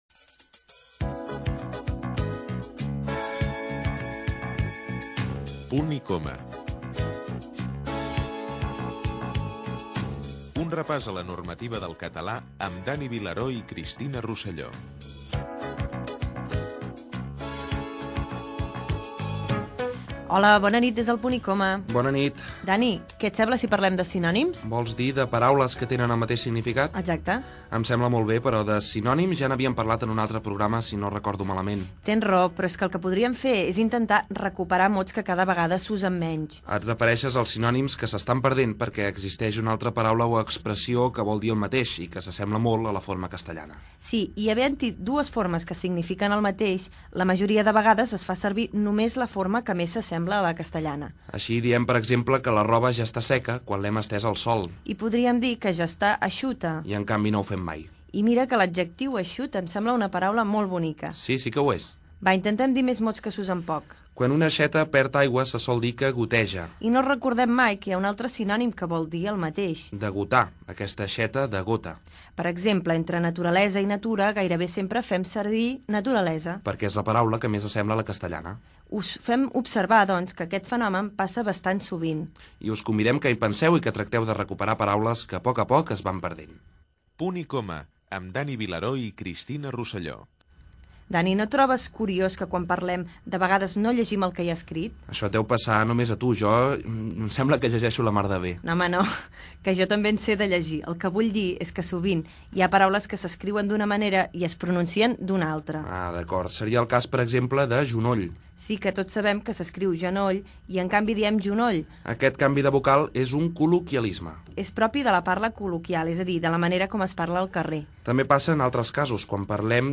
Careta del programa (veu Òscar Dalmau), els sinònims, indicatiu, la mala pronúnica d'algus mots, significat d'una frase feta, resum final, comiat i sintonia de sortida
FM